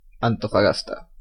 Antofagasta (Spanish pronunciation: [antofaˈɣasta]
Es-Antofagasta.ogg.mp3